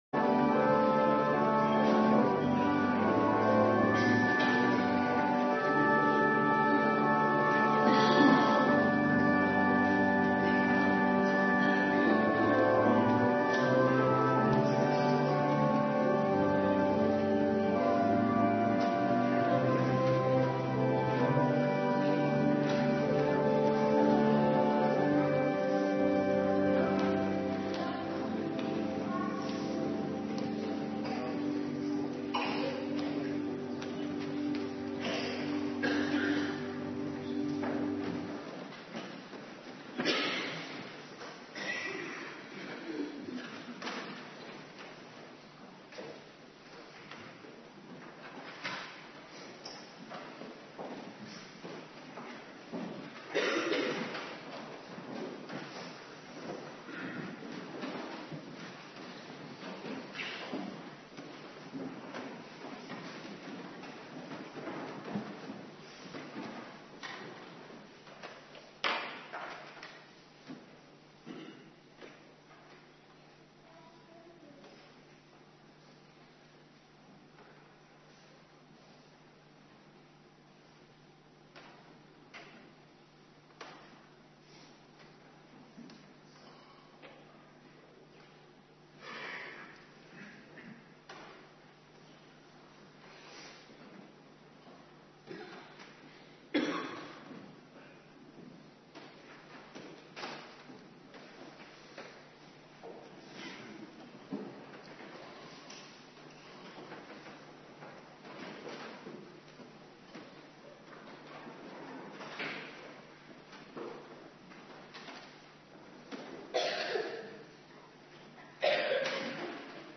Morgendienst
Locatie: Hervormde Gemeente Waarder